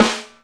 KL.TROMMEL 1.wav